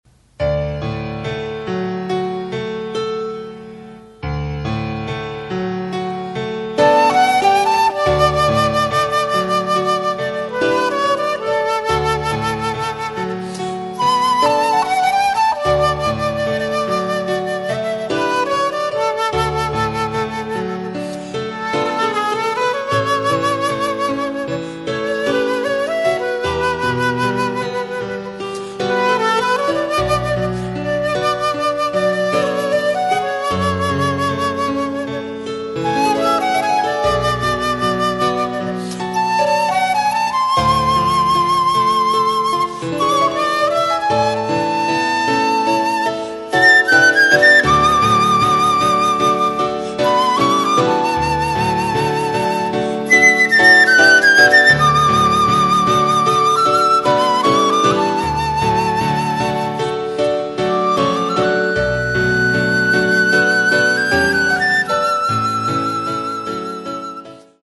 Grabaciones directas en matrimonios y ceremonias en que han participado solistas vocales e instrumentales, grupos de cámara, además de cantantes y músicos invitados, a través de la Agrupación Musical Laudate Dominum.
solistas en un matrimonio
ENTRADA NOVIOS Air (Mús. del Agua) - Haendel / Ave María – Gounod1 / Buongiorno Principessa – Piovani /  Canon en Re M- Pachelbel1 / Coral Cantata 147-Bach1 / For the love of a princess / Gloria - Vivaldi / Ha venido – Haendel1 / Ha venido – Haendel2 / La Conquista del Paraíso- Vangelis / La vita e bella – Piovani / Largo – Haendel / Leyendas de una pasión / Marcha Nupcial –Mendelssohn1 / Marcha Nupcial – Wagner / Oboe de Gabriel – Morricone1 / Pompa y Circunstancia – Elgar1 /